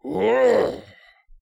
ZS嘲弄2.wav
ZS嘲弄2.wav 0:00.00 0:01.42 ZS嘲弄2.wav WAV · 122 KB · 單聲道 (1ch) 下载文件 本站所有音效均采用 CC0 授权 ，可免费用于商业与个人项目，无需署名。
人声采集素材/男3战士型/ZS嘲弄2.wav